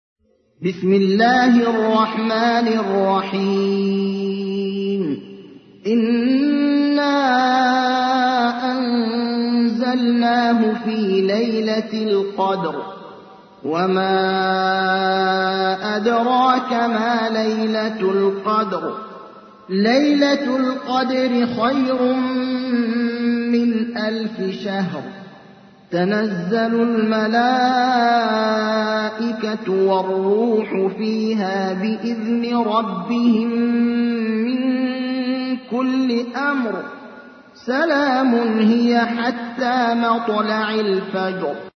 تحميل : 97. سورة القدر / القارئ ابراهيم الأخضر / القرآن الكريم / موقع يا حسين